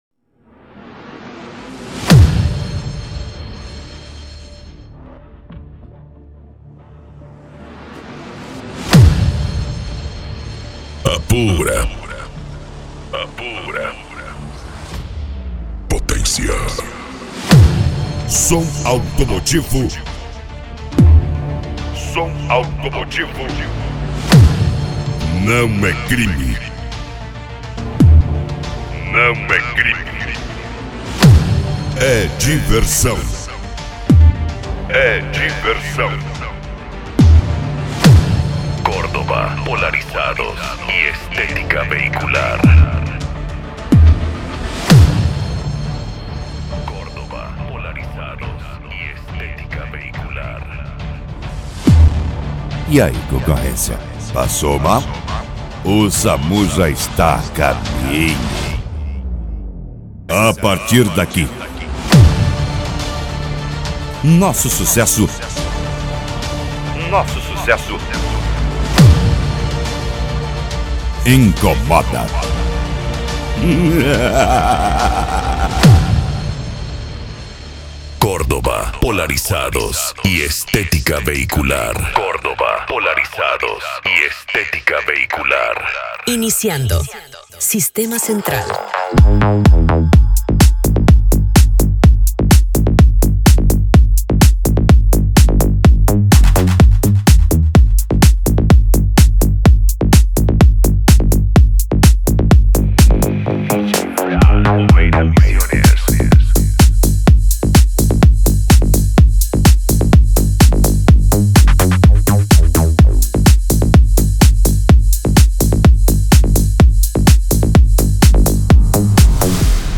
Bass
Electro House
Eletronica
Musica Electronica